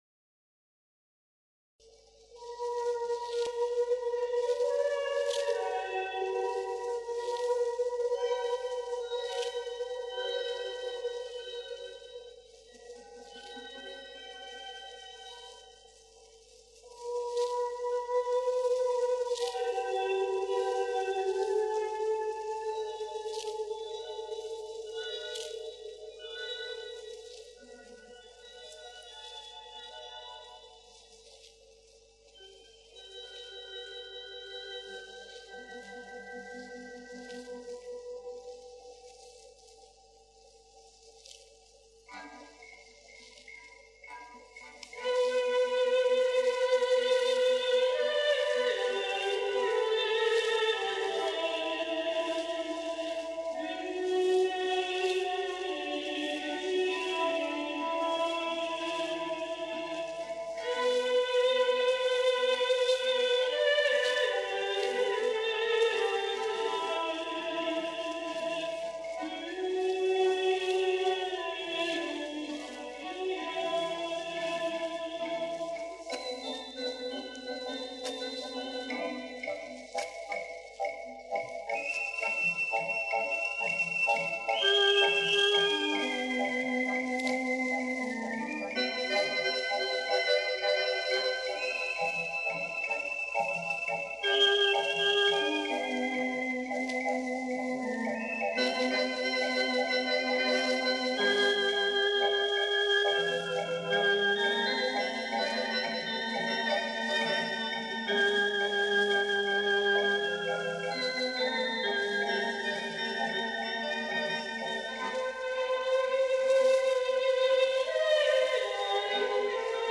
小合奏